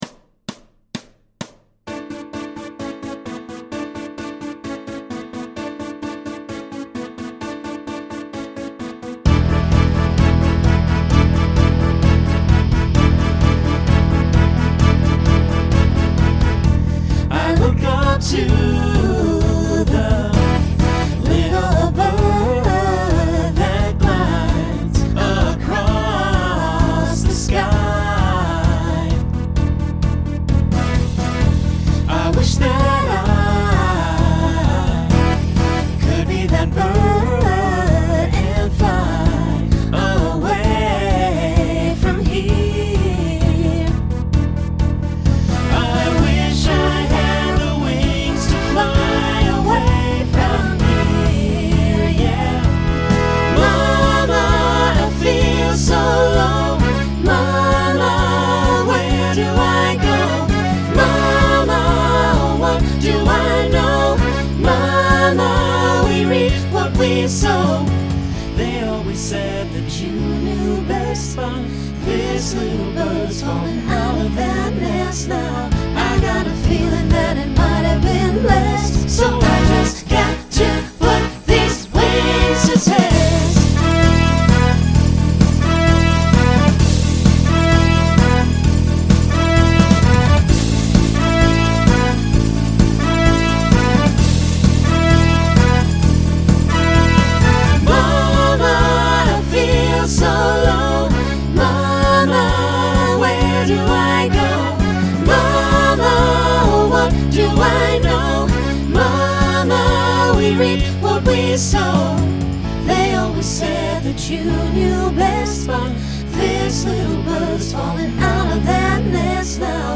Genre Rock Instrumental combo
Voicing SAB